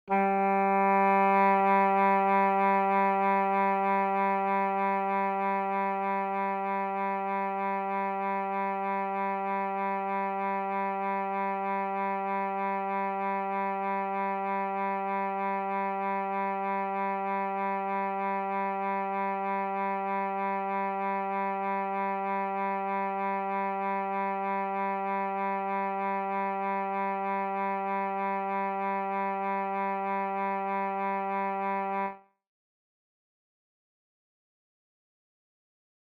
perfectly tuned G tone, as played by an oboe, the usual tuning instrument for any symphonic orchestra.